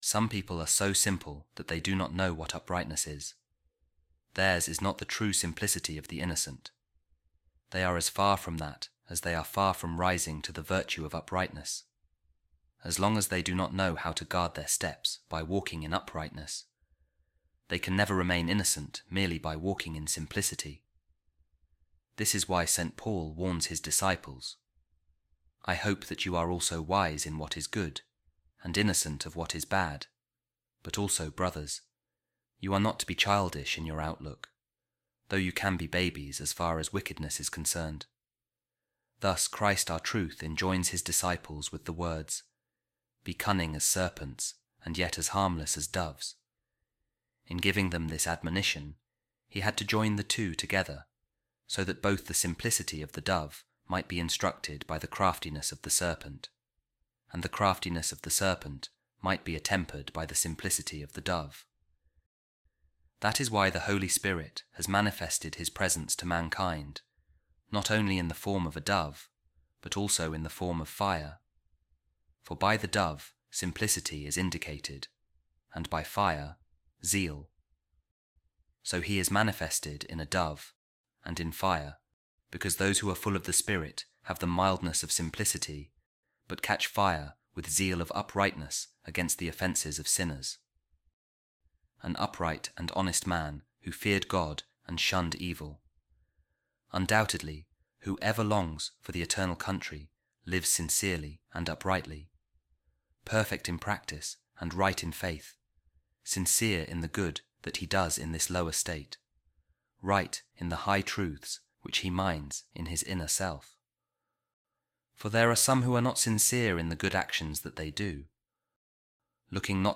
Office Of Readings | Week 8 Sunday | A Commentary On Job By Pope Saint Gregory The Great | A Man Blameless And Upright, One Who Feared God